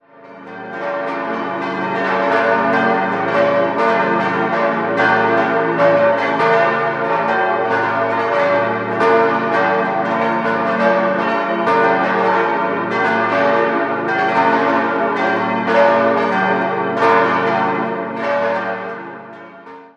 6-stimmiges erweitertes Salve-Regina-Geläute: b°-d'-f'-g'-b'-d'' Die kleine Glocke stammt aus dem Jahr 1924 und wurde bei Grassmayr in Innsbruck gegossen, alle anderen entstanden 1972 bei Oberascher in Salzburg.